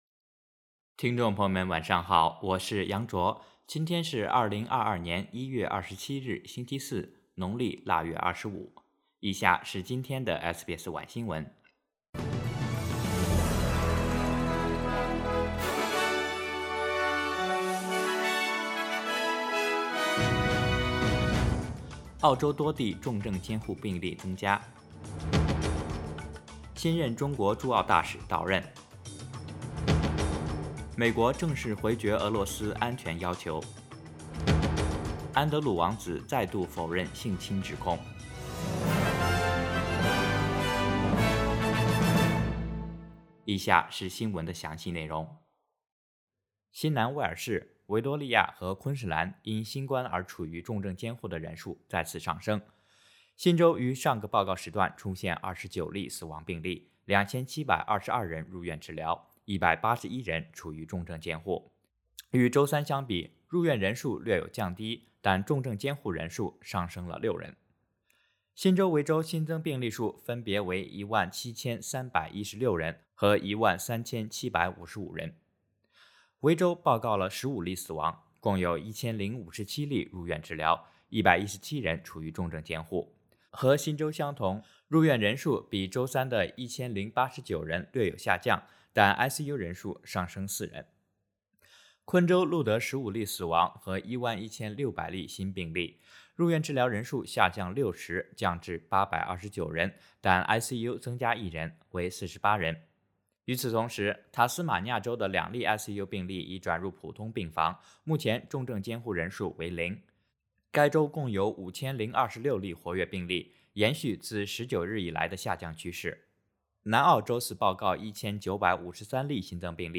SBS晚新闻（2022年1月27日）
SBS Mandarin evening news Source: Getty Images